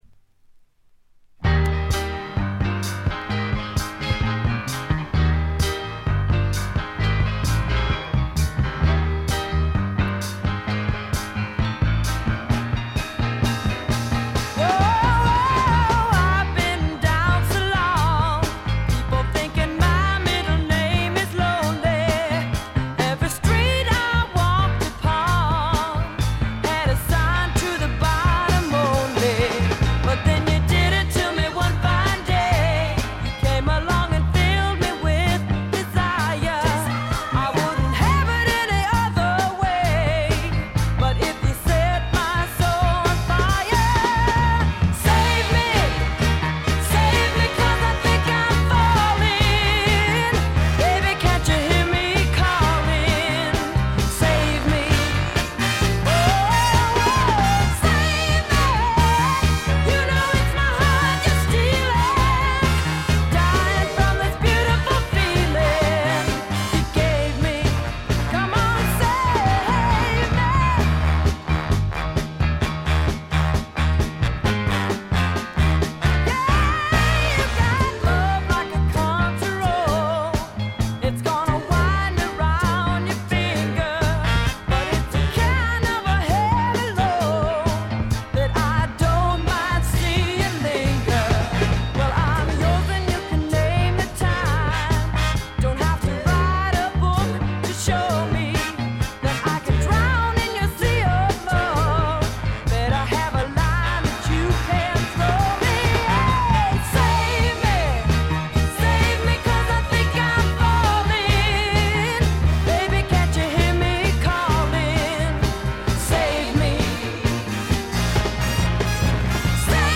軽微なチリプチ少々。
スワンプ系女性ヴォーカル・アルバムの快作です。
試聴曲は現品からの取り込み音源です。